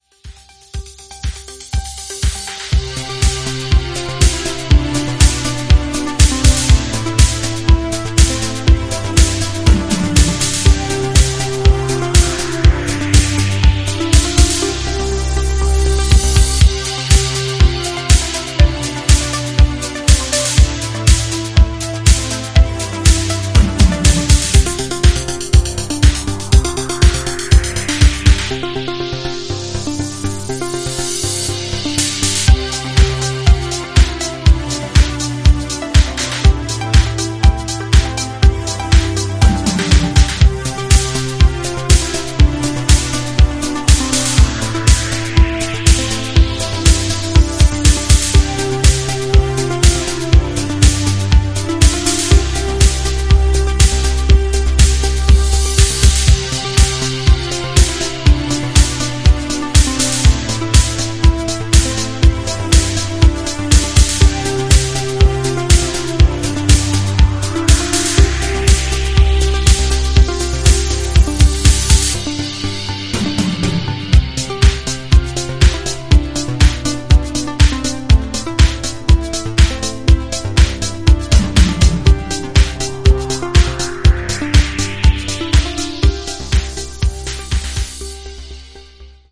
viola
energetic electro glam stomp. A cracking kosmische, motorik